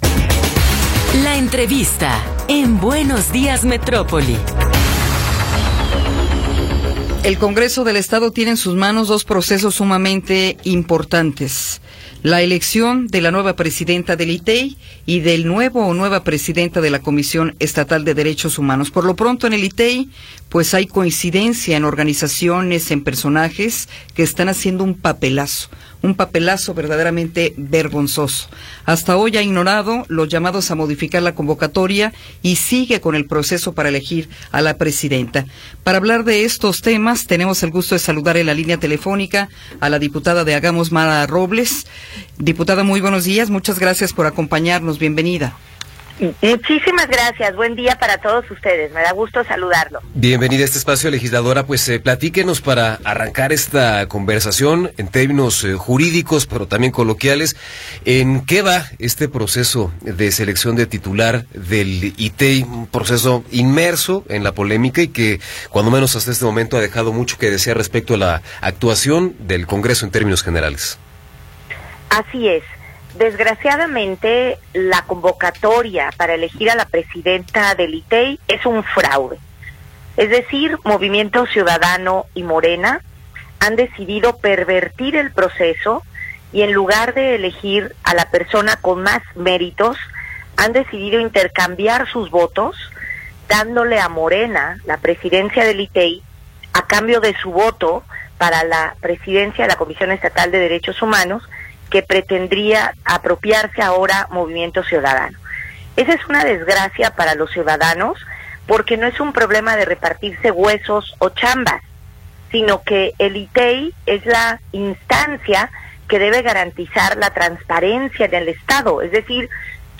Entrevista con Mara Robles Villaseñor